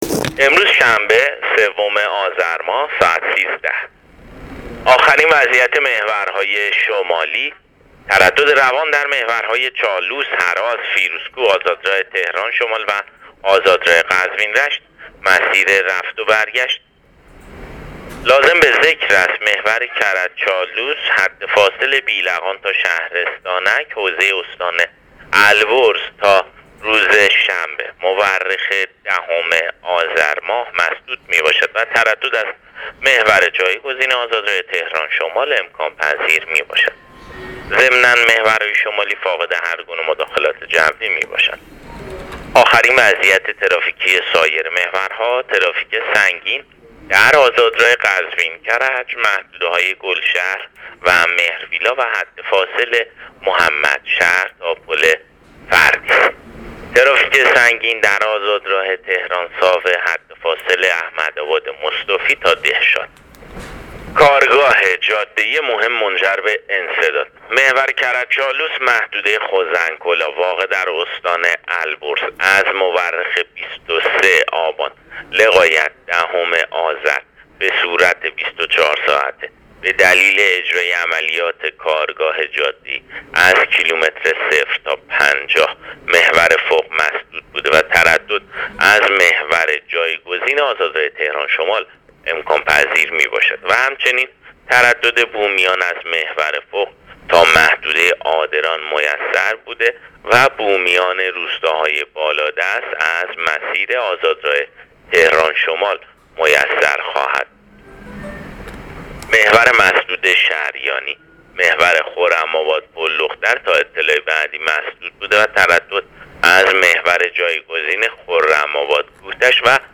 گزارش رادیو اینترنتی از آخرین وضعیت ترافیکی جاده‌ها تا ساعت ۱۳ سوم آذر؛